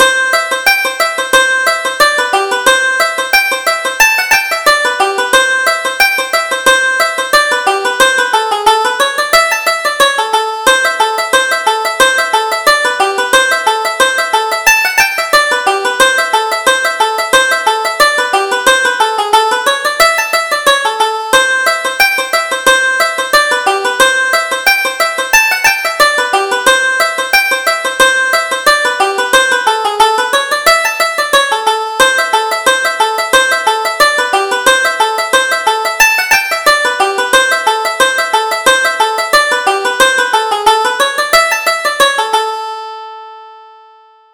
Reel: The Jolly Seven